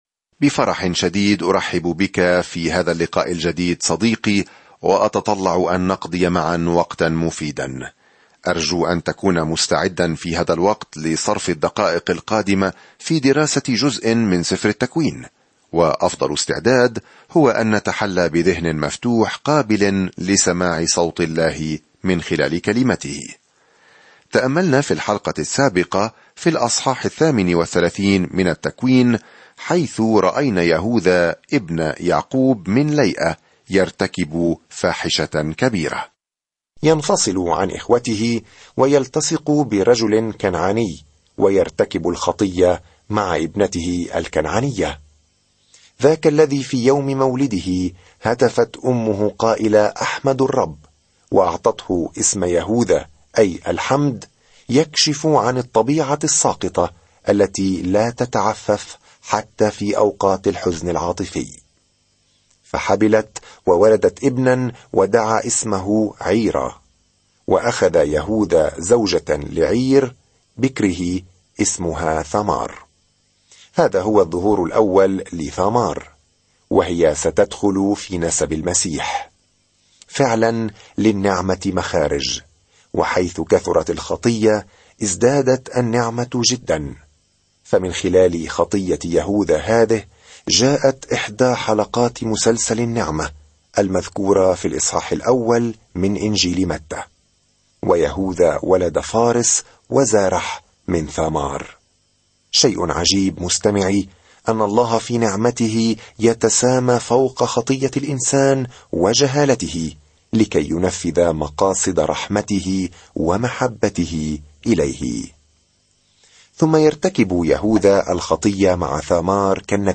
سافر يوميًا عبر سفر التكوين وأنت تستمع إلى الدراسة الصوتية وتقرأ آيات مختارة من كلمة الله.